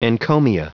Prononciation du mot encomia en anglais (fichier audio)
Prononciation du mot : encomia